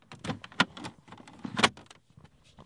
描述：猎枪座位的车厢
Tag: 打开 关闭 车室